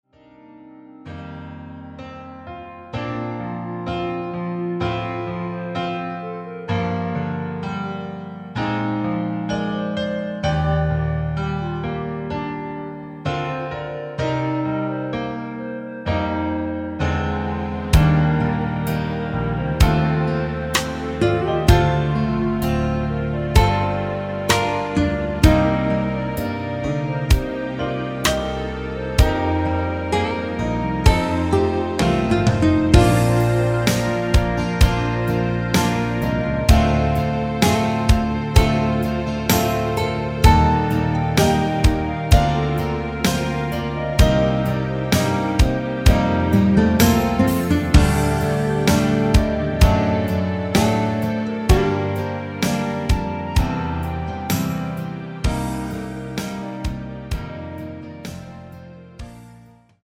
Bb
멜로디 MR이란
앞부분30초, 뒷부분30초씩 편집해서 올려 드리고 있습니다.